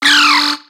Cri de Tritonde dans Pokémon X et Y.